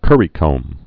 (kûrē-kōm, kŭr-)